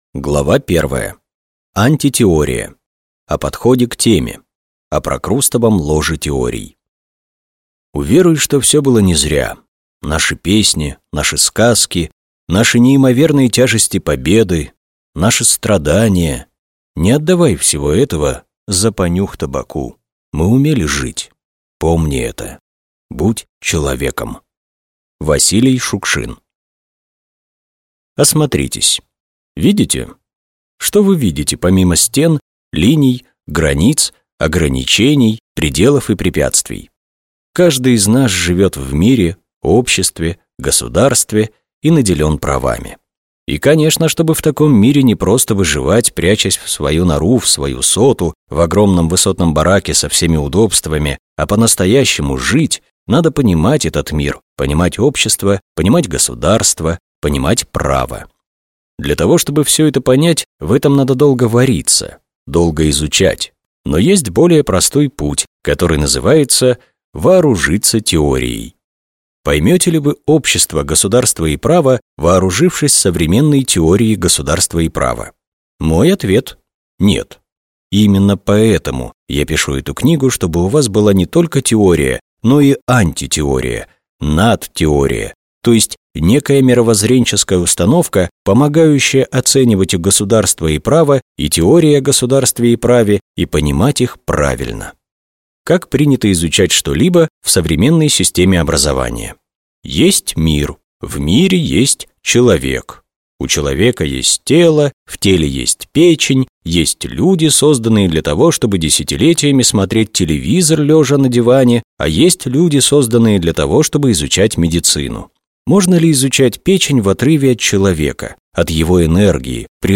Аудиокнига Теория государства и права для чайников | Библиотека аудиокниг